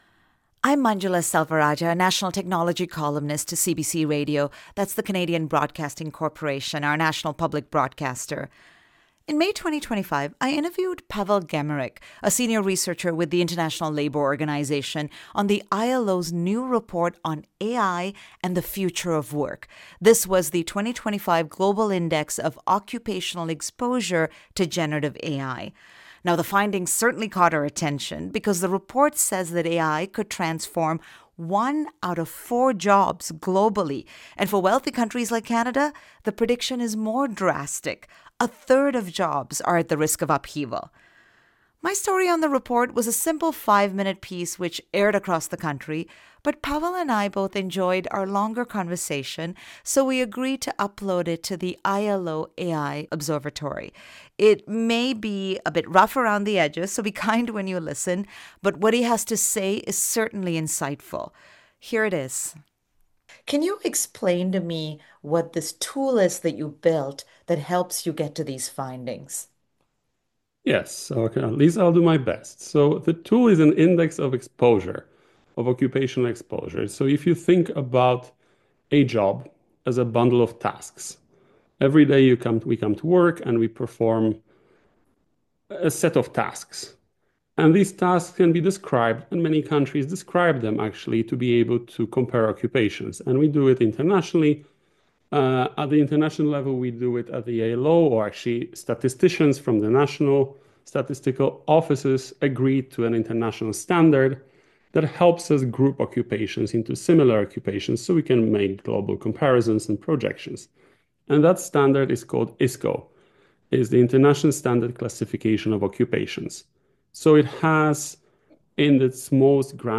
GEN AI Index 2025_Interview CBC Radio One.mp3